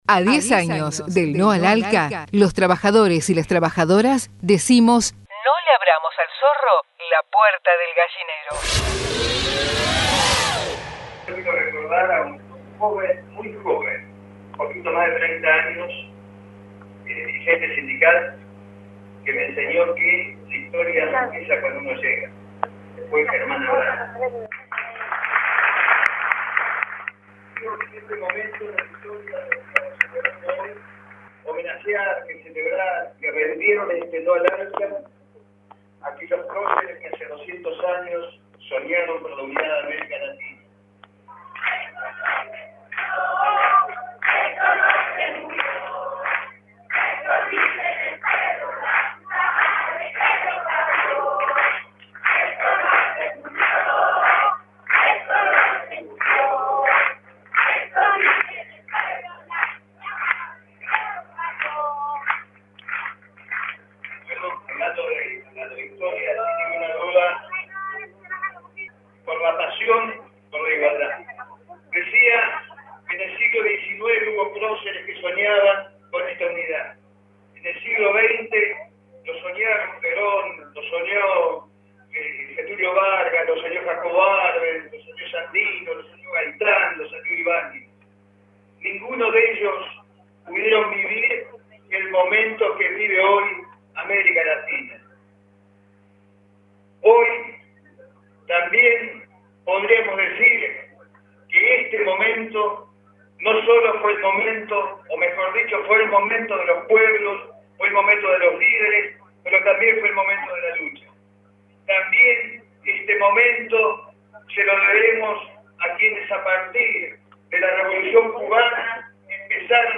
"NO al ALCA" // acto en el Auditorio de FOETRA